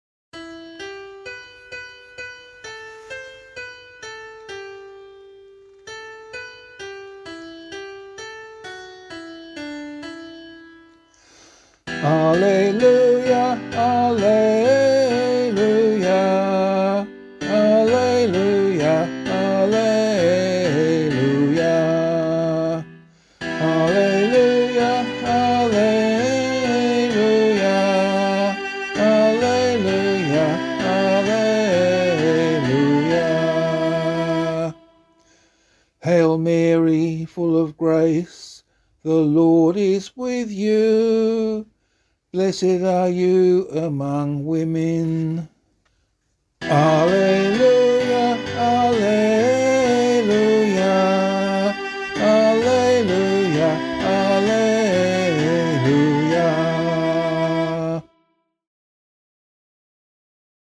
Gospelcclamation for Australian Catholic liturgy.
442 Immaculate Conception Gospel [LiturgyShare A - Oz] - vocal.mp3